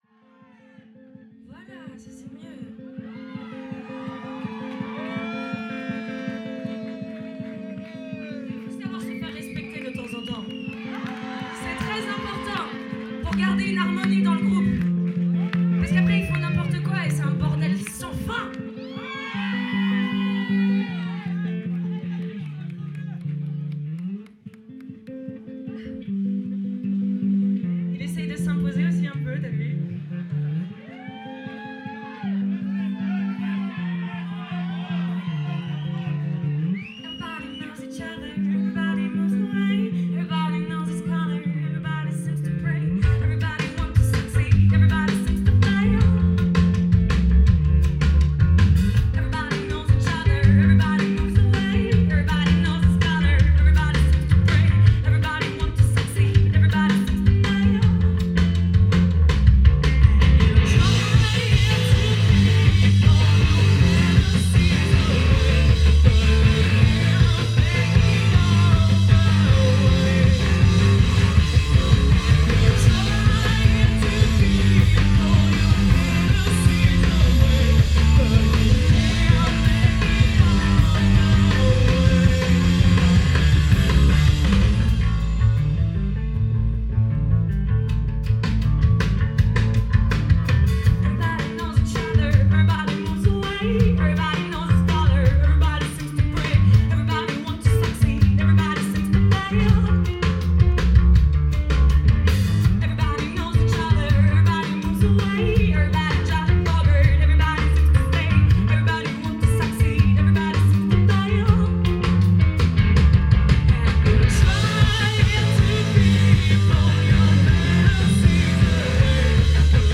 Page 19 - C'est quoi ce live ?